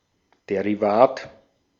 Ääntäminen
IPA: /ˌdeːriˈvaːt/